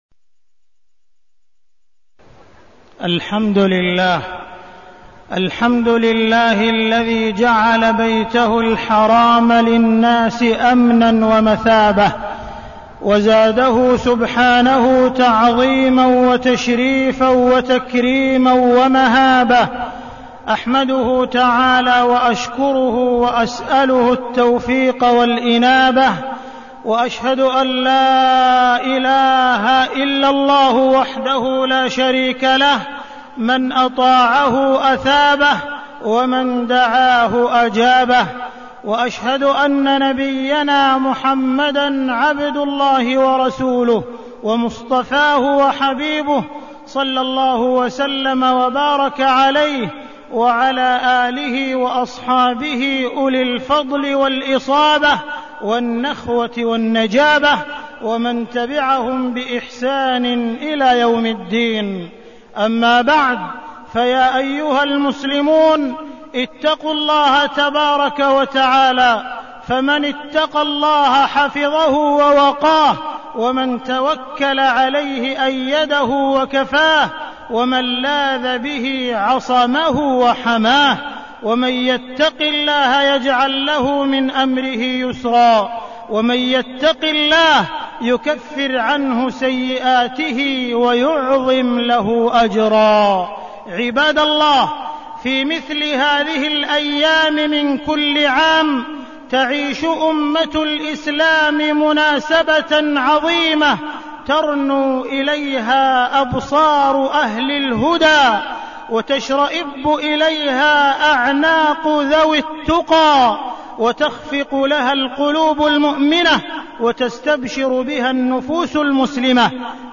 تاريخ النشر ٤ ذو الحجة ١٤١٧ هـ المكان: المسجد الحرام الشيخ: معالي الشيخ أ.د. عبدالرحمن بن عبدالعزيز السديس معالي الشيخ أ.د. عبدالرحمن بن عبدالعزيز السديس فريضة الحج